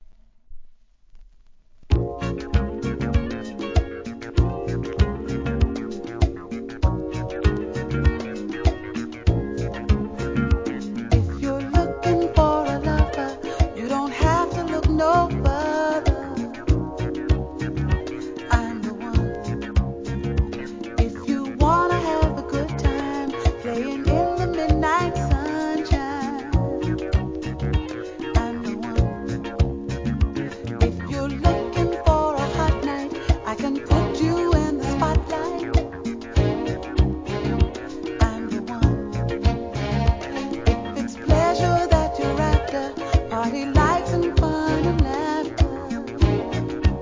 SOUL/FUNK/etc...
NICEメロ〜